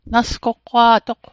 Pronunciation Guide: nas·kohk·waa·dohk